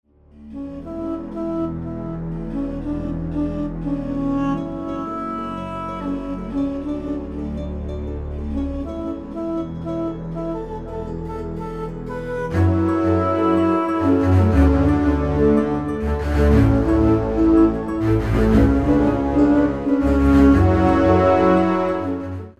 Muzyka o charakterze muzyki filmowej.